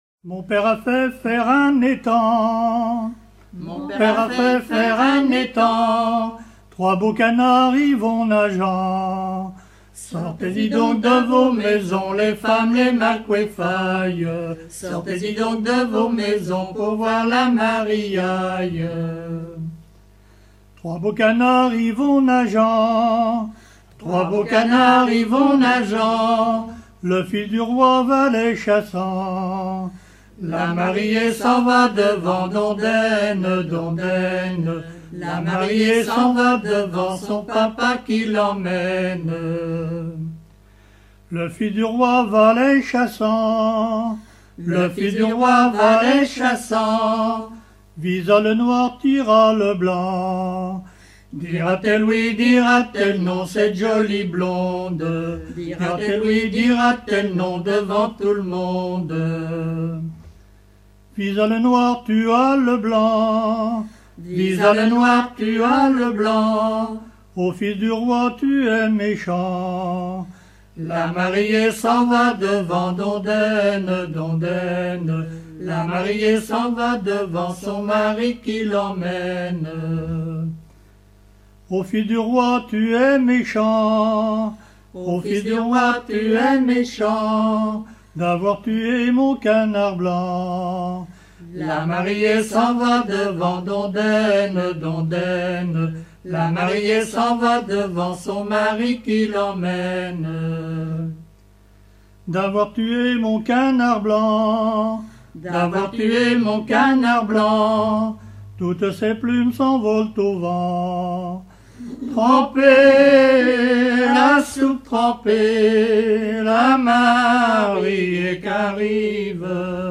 gestuel : à marcher
circonstance : fiançaille, noce
Genre laisse